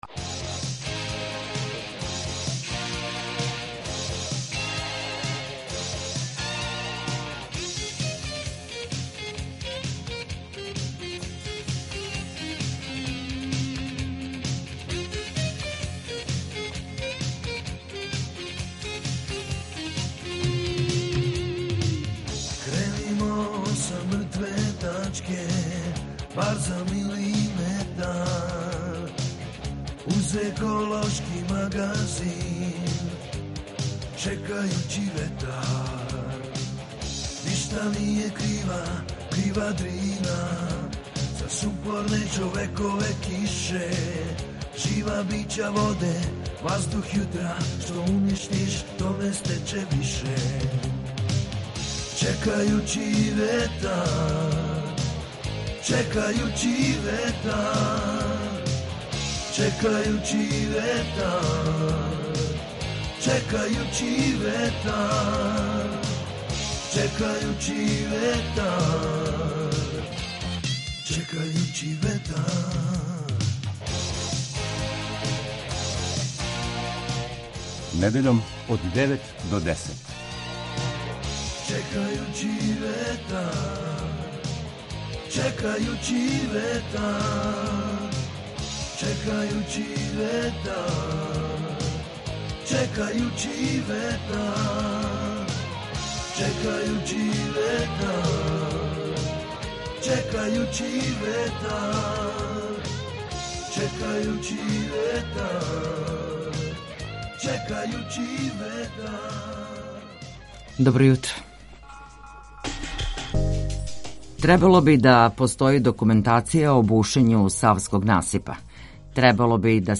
Čekajući vetar - ekološki magazin Radio Beograda 2 koji se bavi odnosom čoveka i životne sredine, čoveka i prirode.